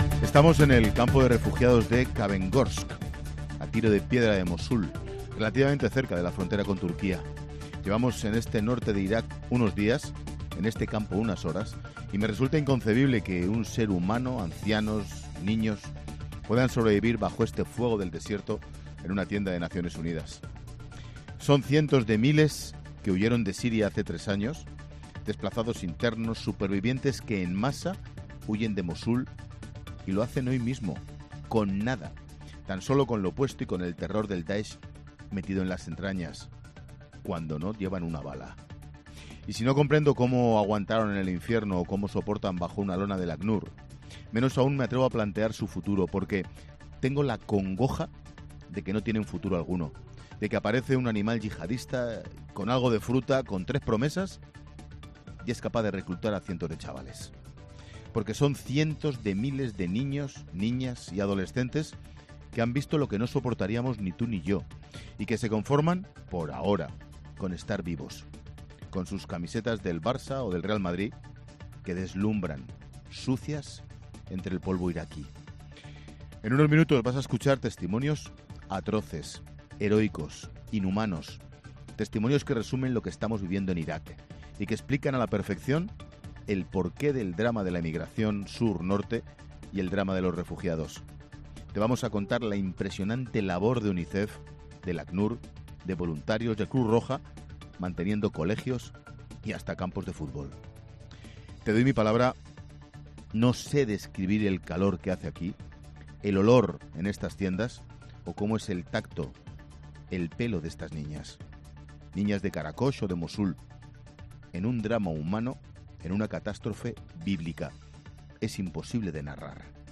Monólogo de Ángel Expósito a las 17h. desde el campo de refugiados de Kawergosk, al norte de Irak, donde residen miles de desplazados y refugiados sirios que han huído del DAESH,